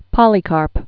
(pŏlē-kärp), Saint AD 69?-155?